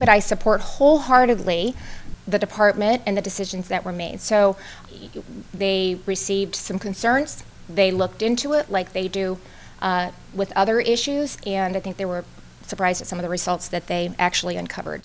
During a news conference in Des Moines yesterday (Wednesday), Reynolds said no one in state government checked in with her about the investigation.